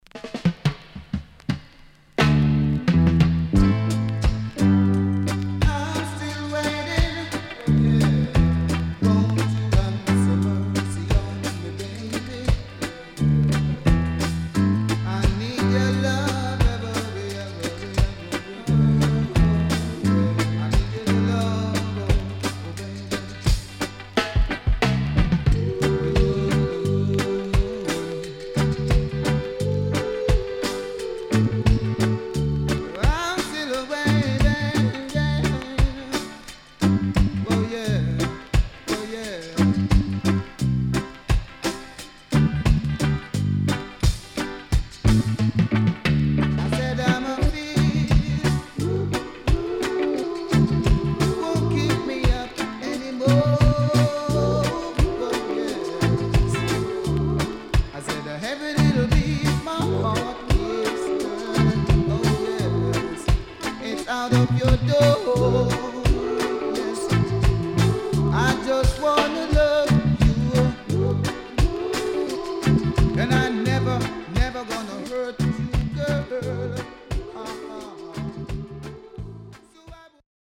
HOME > Back Order [VINTAGE 7inch]  >  KILLER & DEEP
CONDITION SIDE A:VG(OK)〜VG+
Deep Roots Vocal
SIDE A:所々チリノイズがあり、少しプチノイズ入ります。